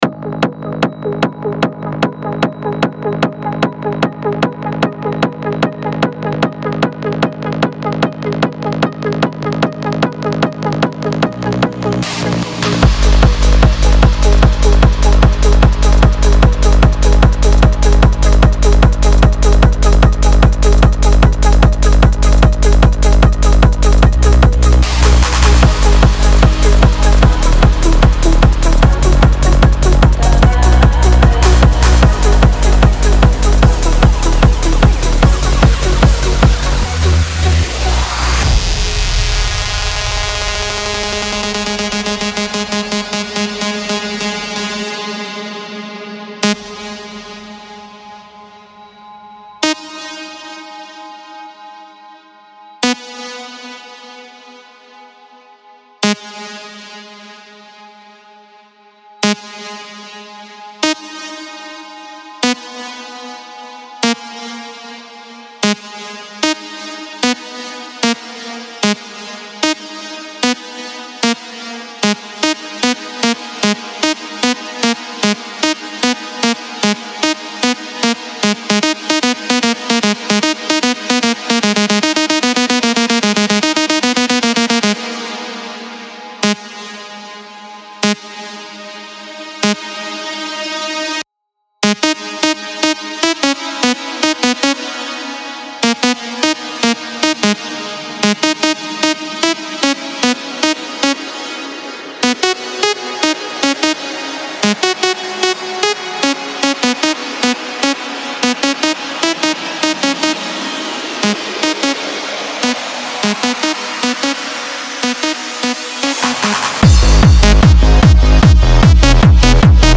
• Жанр: EDM